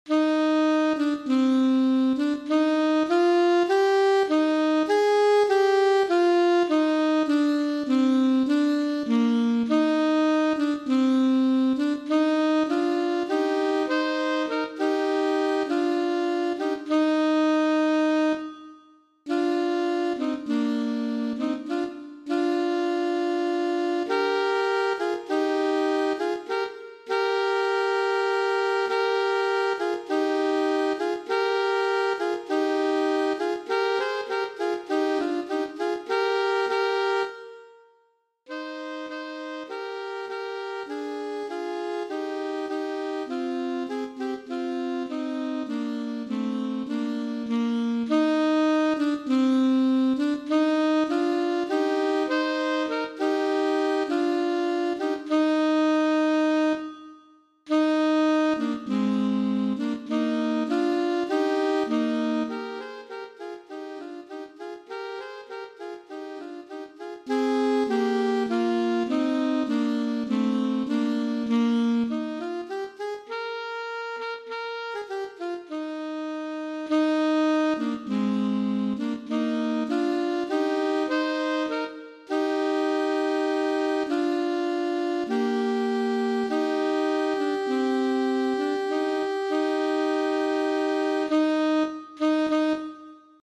• Easy-Medium